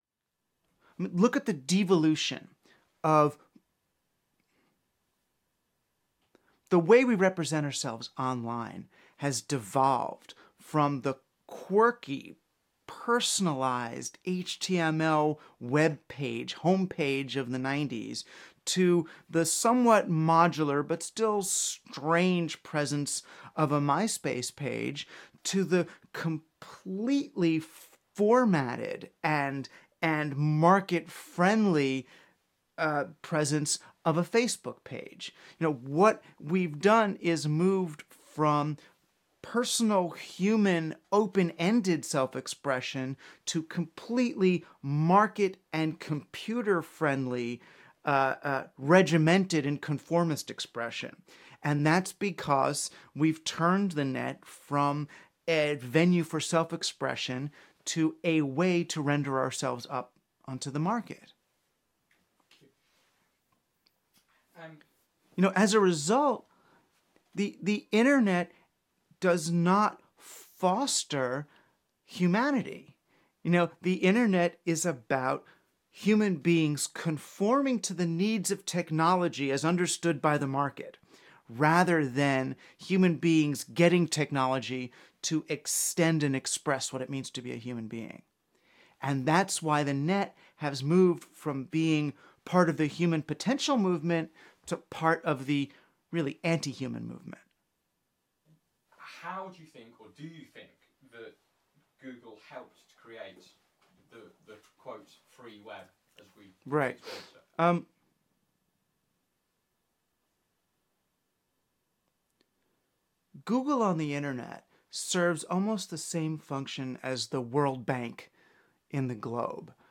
In December 2009 he gave an interview to BBC discussing the realities of ‘free’ content and services on the web.